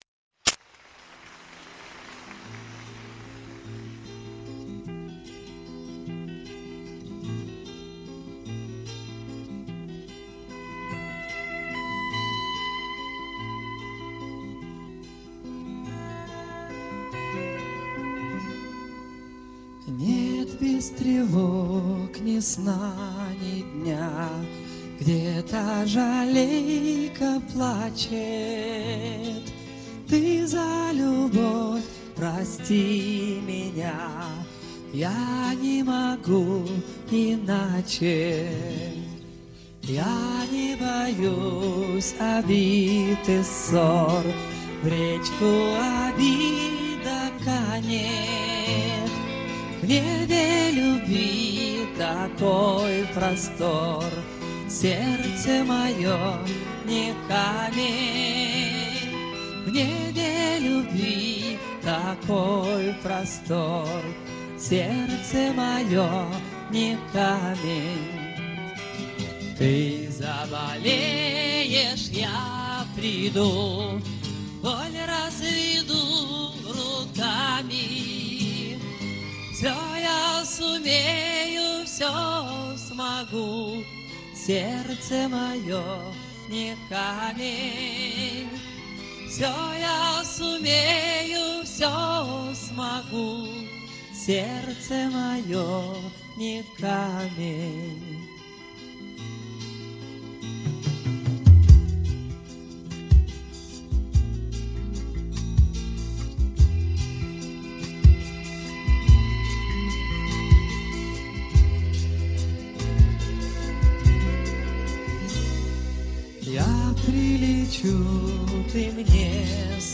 nữ ca sĩ